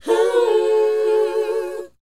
WHOA E B.wav